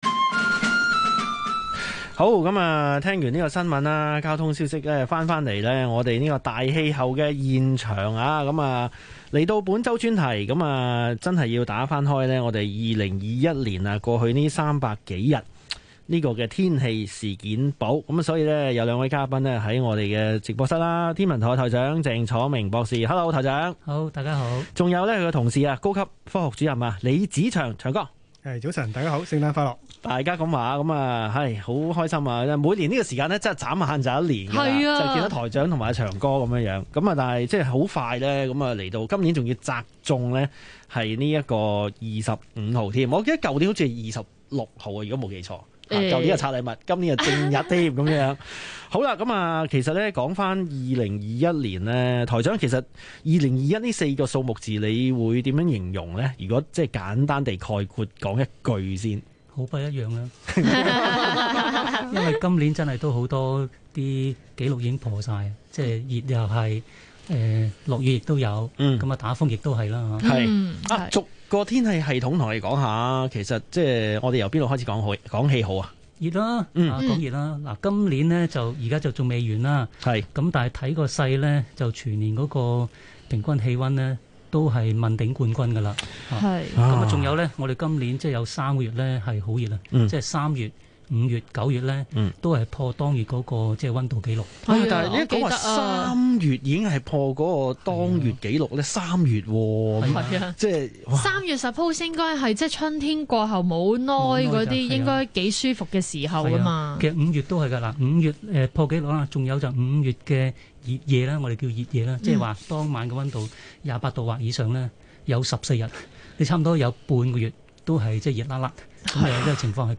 以下是陈栢纬博士在电台的广播录音。 大气候2023天气展望 01.04.2023 香港电台 以下是陈栢纬博士的录影片段。